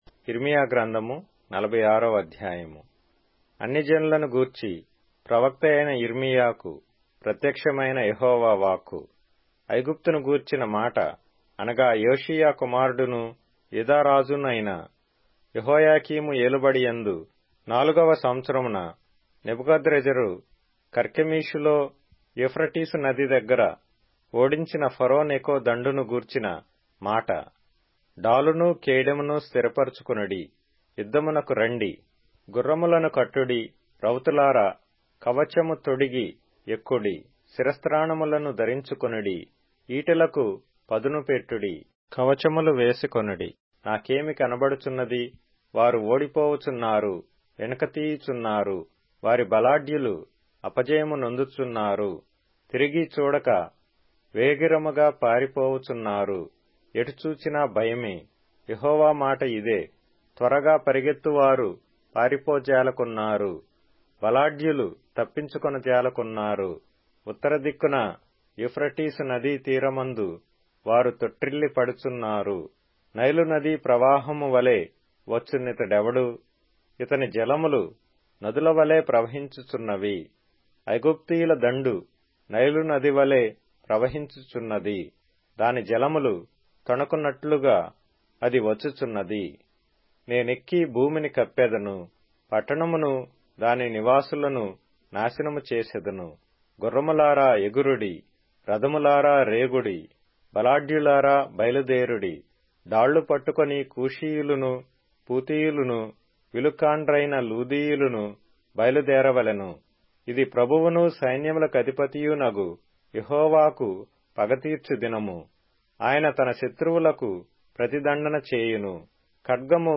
Telugu Audio Bible - Jeremiah 41 in Litv bible version